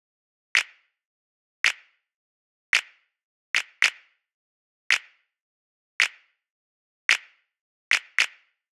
Unison Funk - 9 - 110bpm - Snap.wav